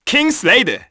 Neraxis-Vox_Kingsraid_kr.wav